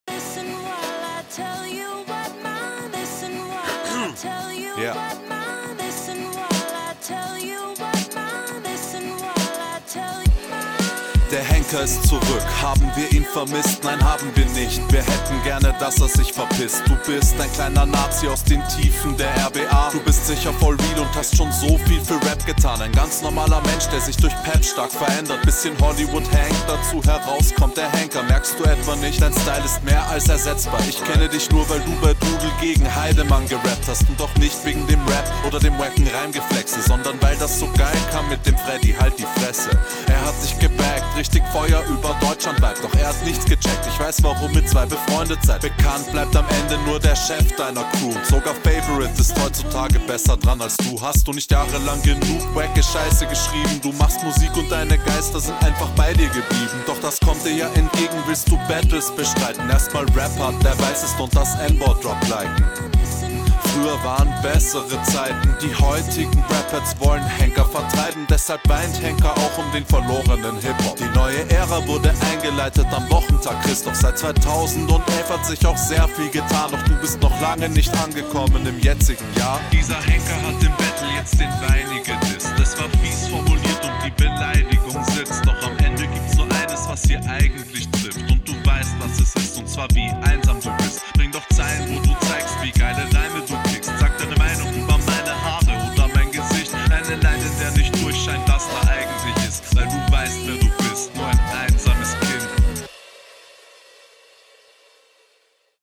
Flow und Stimme klingen bei dir halt schon wieder so gleich :D Rappen kannst du, …